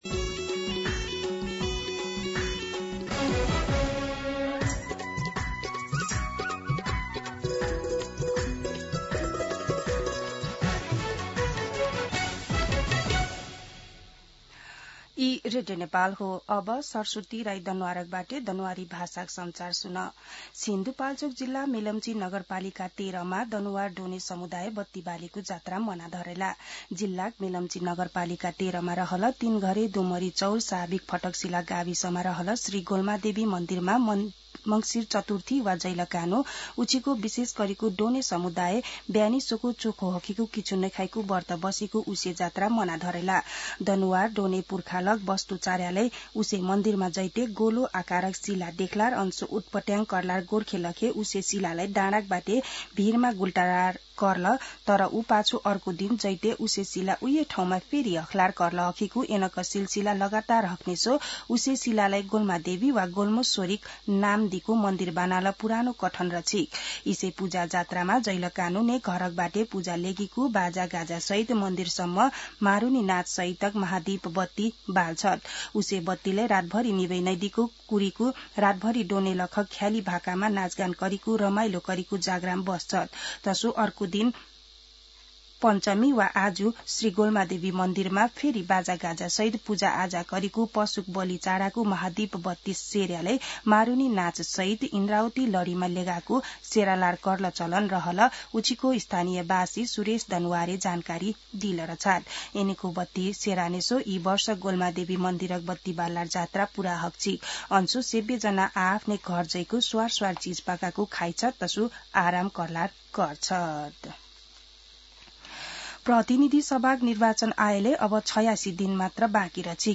An online outlet of Nepal's national radio broadcaster
दनुवार भाषामा समाचार : २३ मंसिर , २०८२
Danuwar-News-8-23.mp3